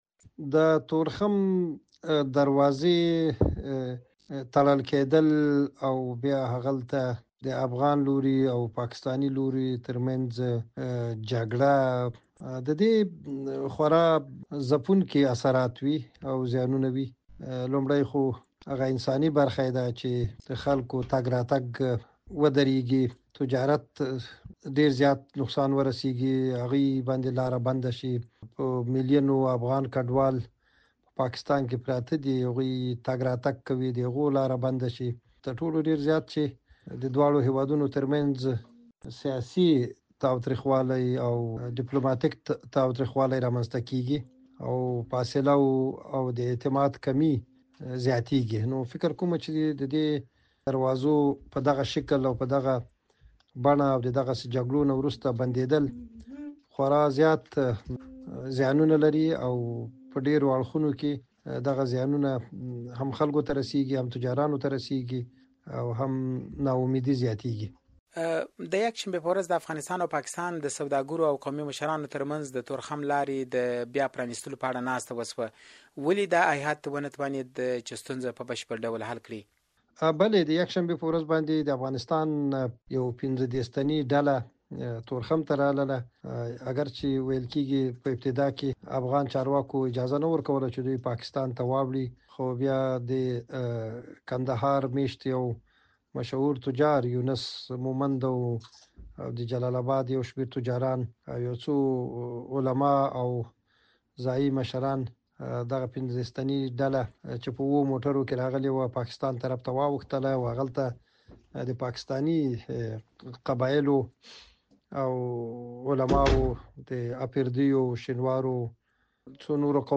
راډیويي مرکې